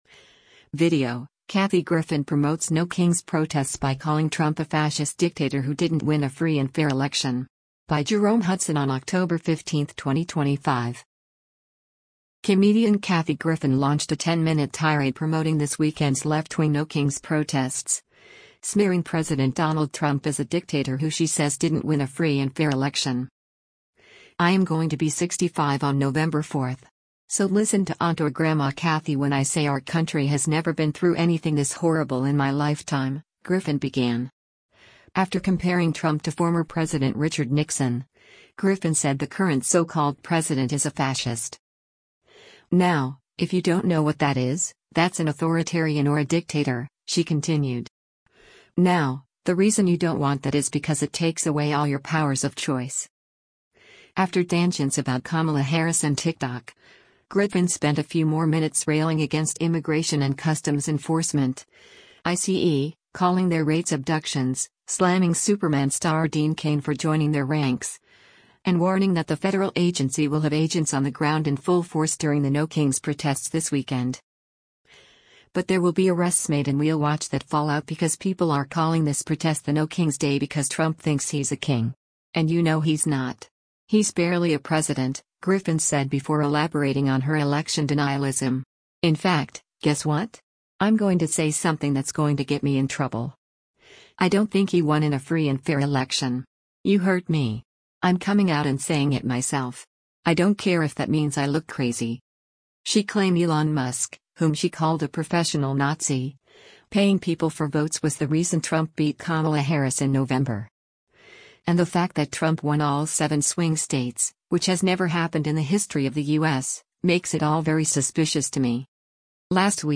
Comedian Kathy Griffin launched a 10-minute tirade promoting this weekend’s left-wing “No Kings” protests, smearing President Donald Trump as a “dictator” who she says “didn’t win a free and fair election.”